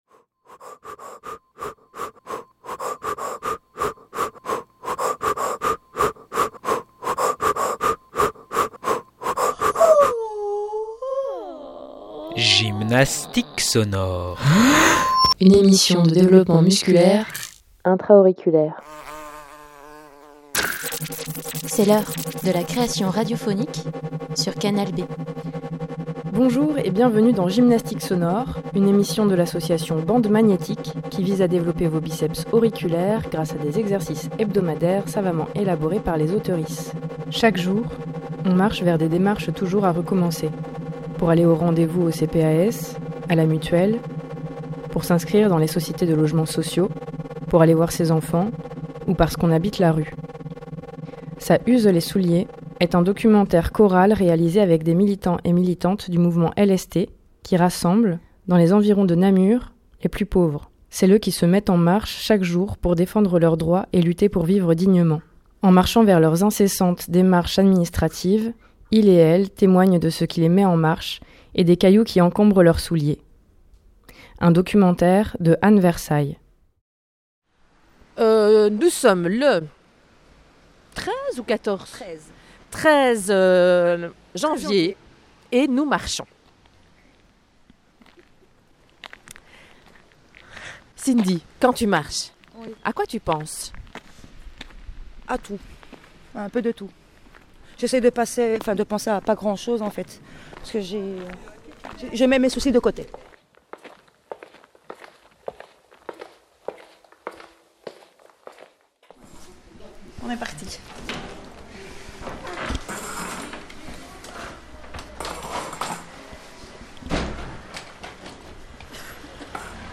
En marchant vers leurs incessantes démarches administratives, iels témoignent de ce qui les met en marche et des cailloux qui encombrent leurs souliers.